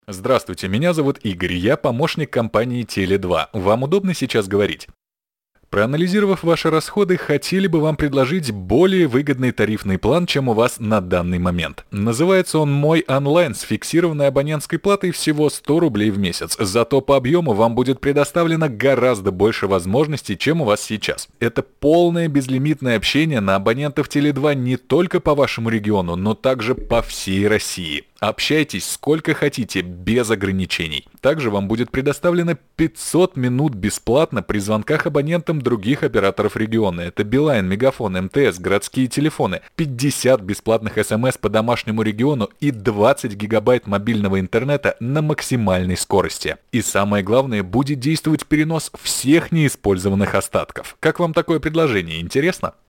Демо-запись, робот для мобильного провайдера
tele2-demo25.mp3